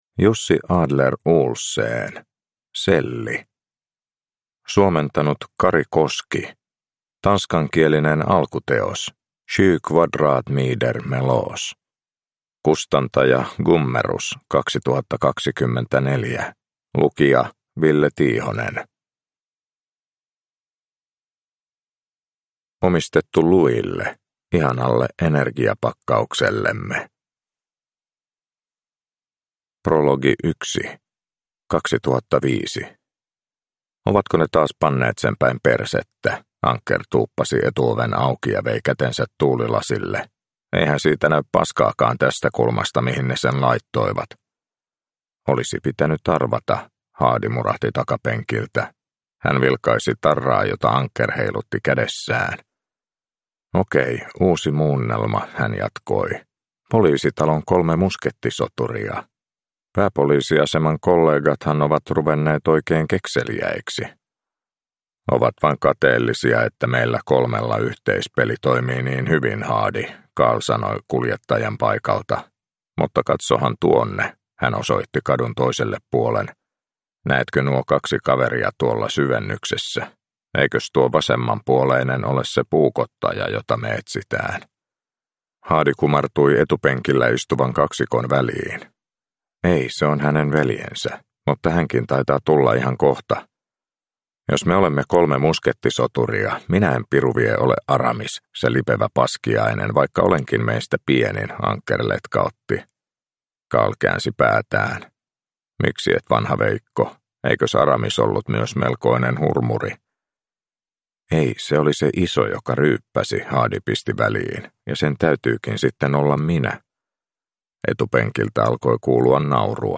Selli – Ljudbok